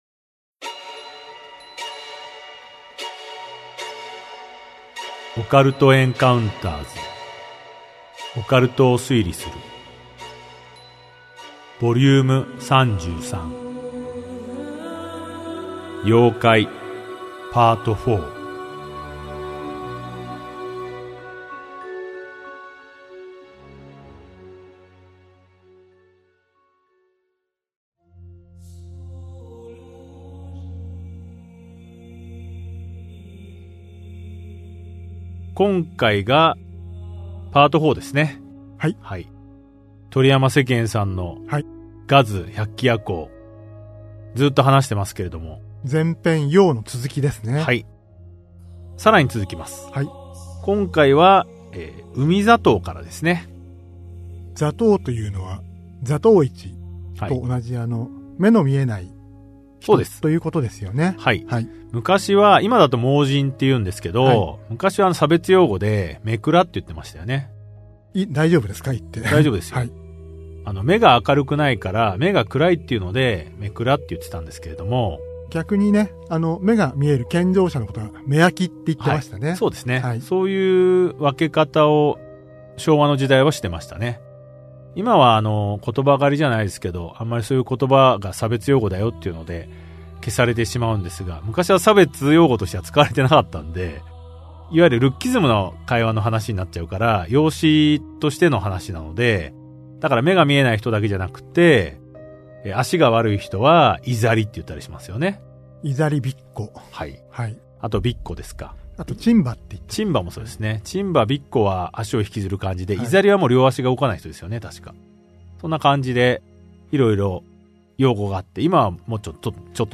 オカルト・エンカウンターズの二人が伝承と文献を紐解き、伝説の裏側を推理する──。